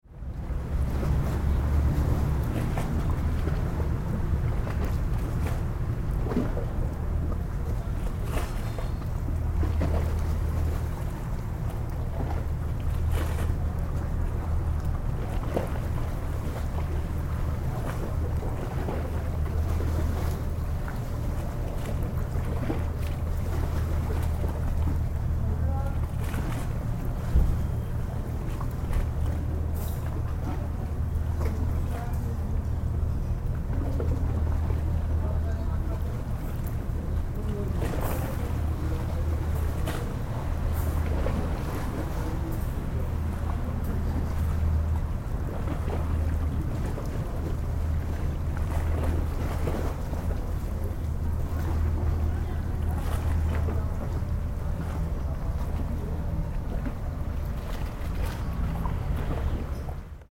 Kuzguncuk
You can hear the waves splashing against the stone pavement and the distant hum of the cars crossing the Bosphorus bridge.